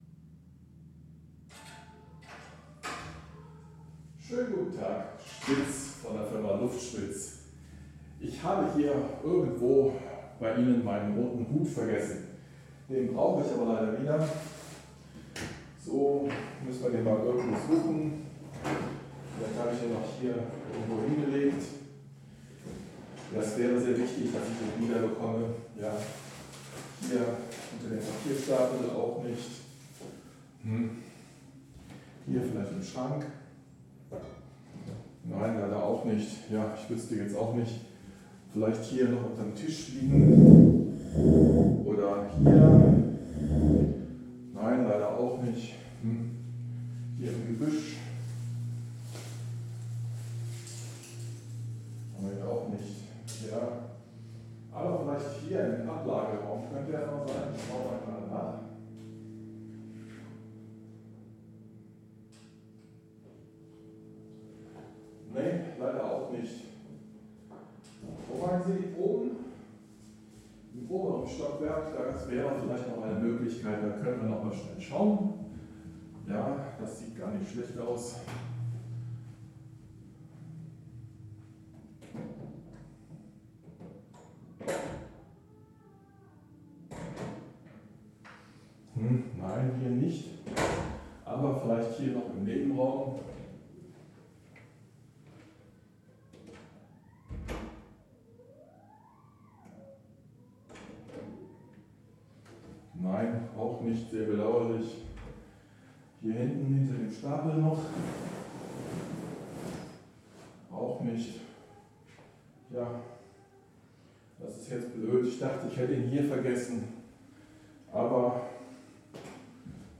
ORTF-3D Test 1.mp3